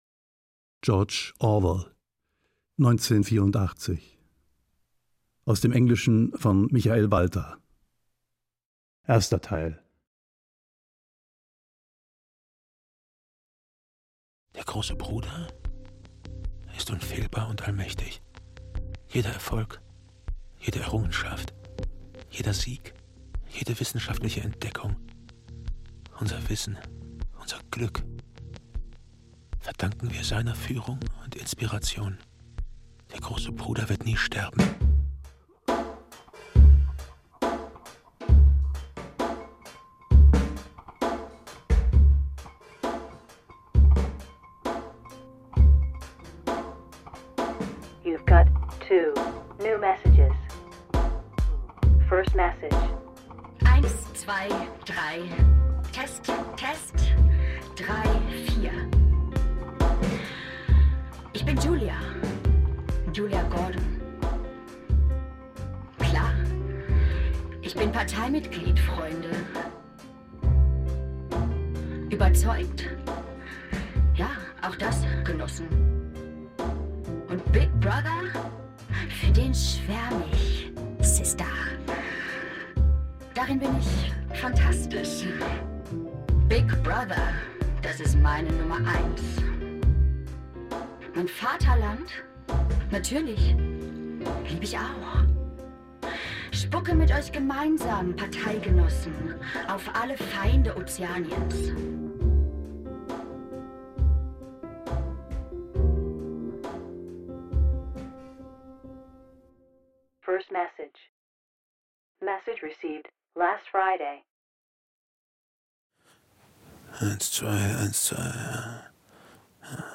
1984 - Hörspiel nach George Orwell | BR Podcast